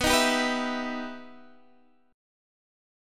B Major Flat 6th
BM-611 chord {x 2 1 0 0 0} chord